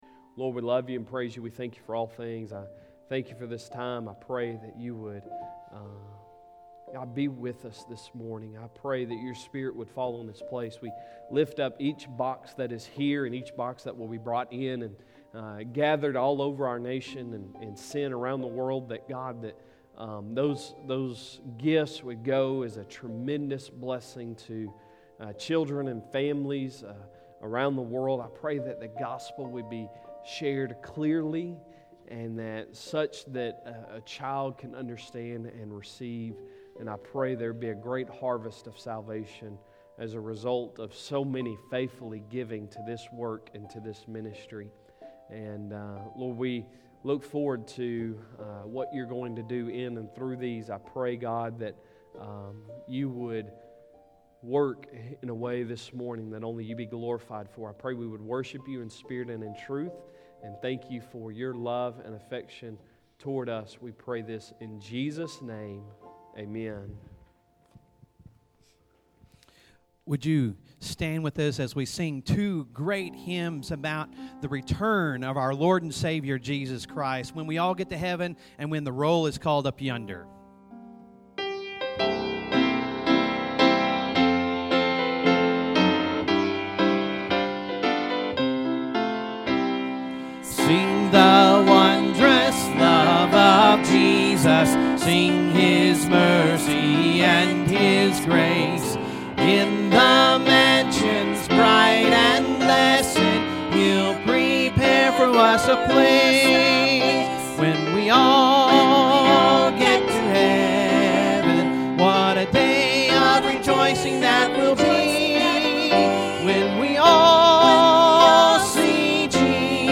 Sunday Sermon November 15, 2020